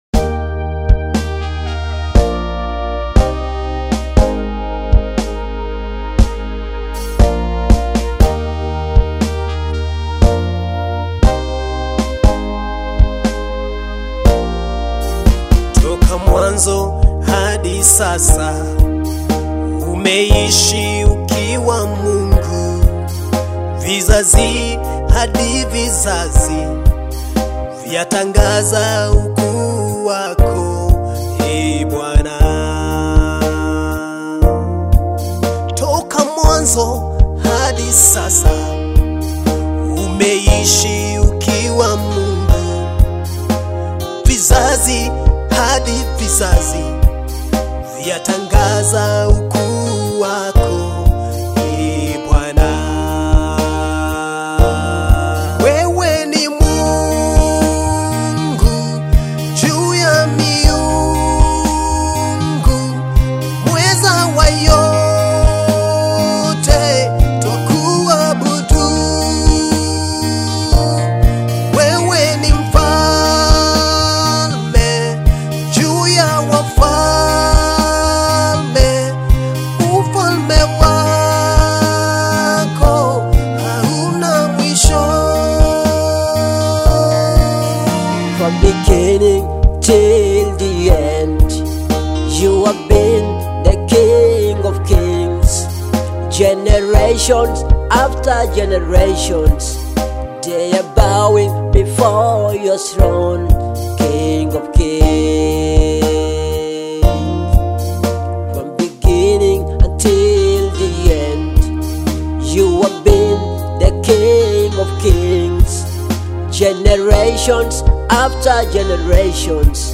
Swahili worship song